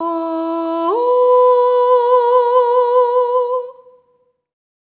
SOP5TH E4A-R.wav